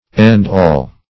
End-all \End"-all`\, n.